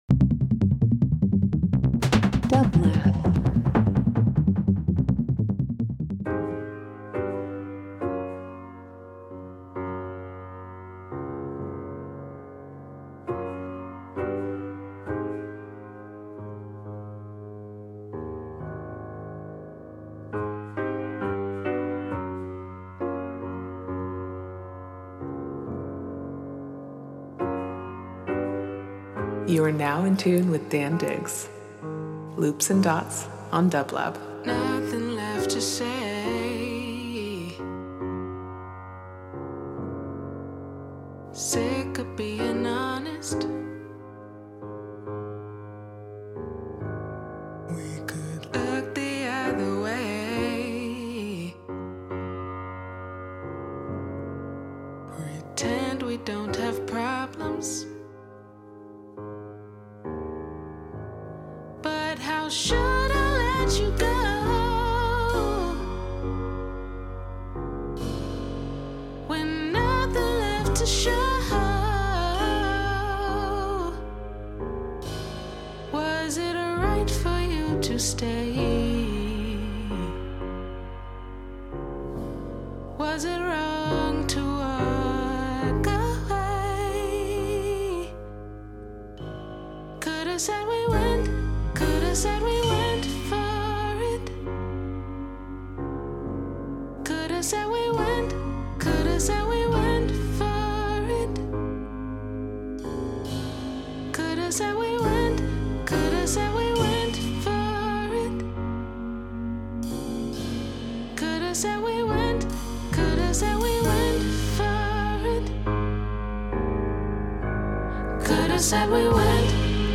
Electronic Indie Leftfield Soul